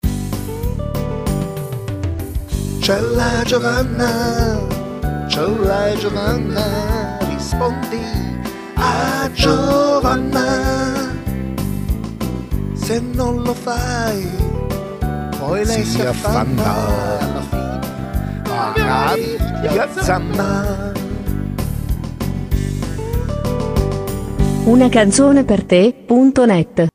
Una suoneria personalizzata che canta